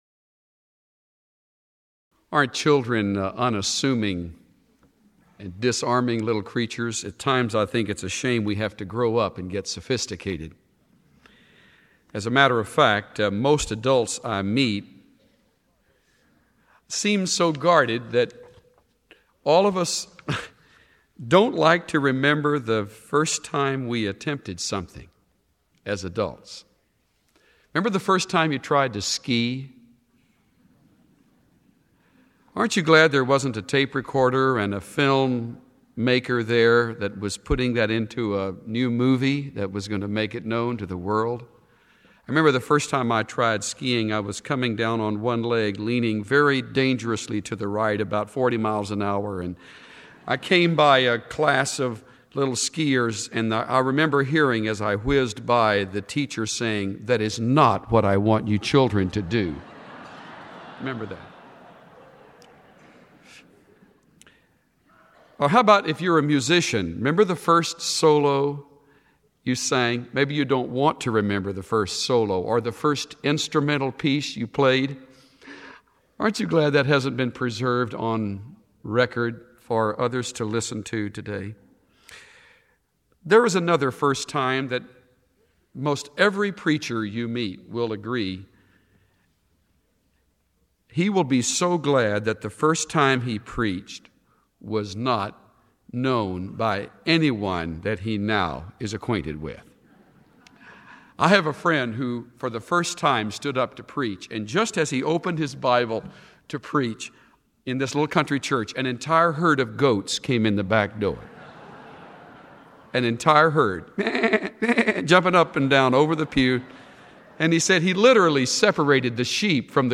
Listen to Chuck Swindoll’s overview of First John in his audio message from the Classic series God’s Masterwork.